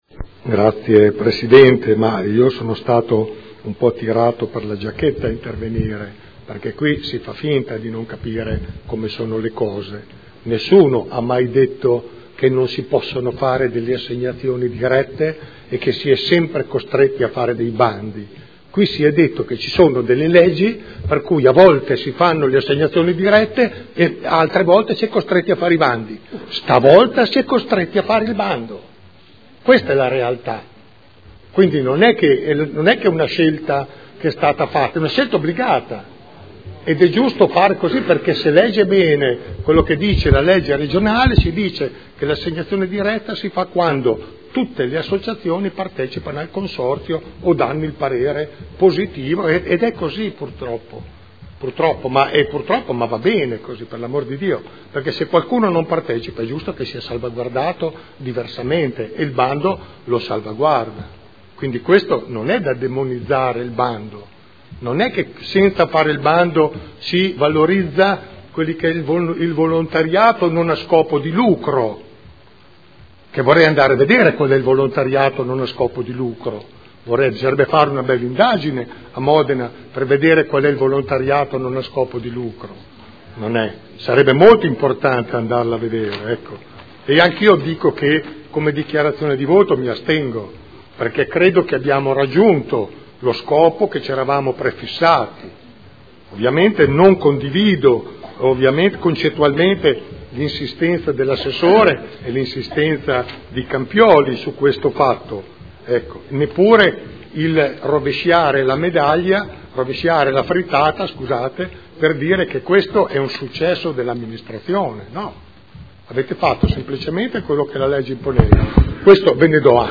Seduta del 16 gennaio. Proposta di deliberazione: Concessione gestione piscina Dogali: indirizzi. Dichiarazioni di voto